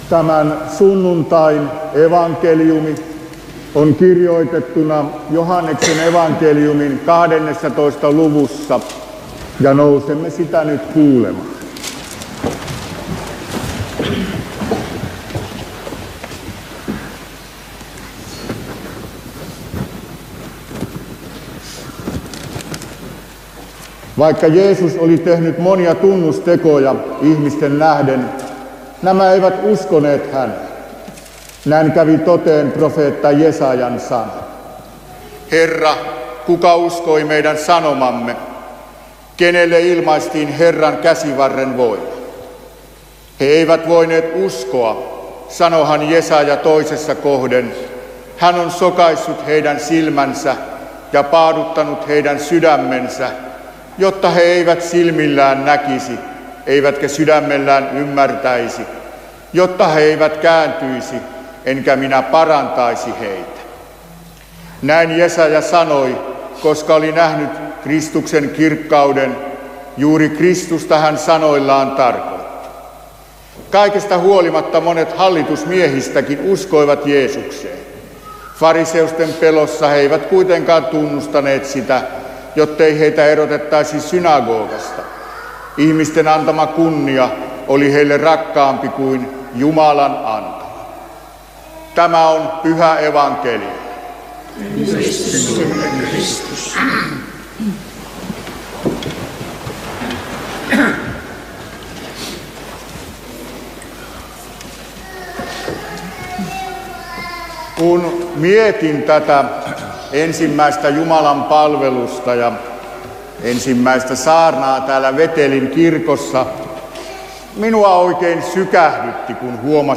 Veteli